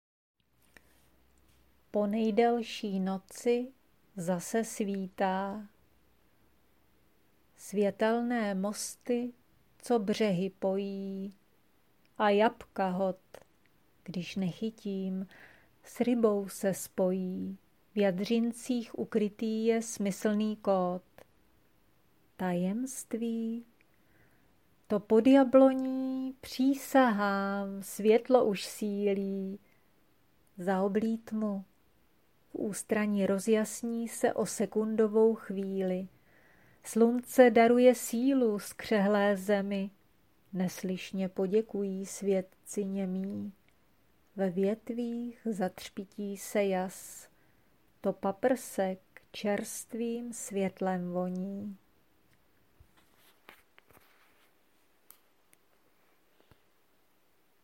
Přičichl jsem atmosféry a nachýlil vjem k tvému hlasu rolniček.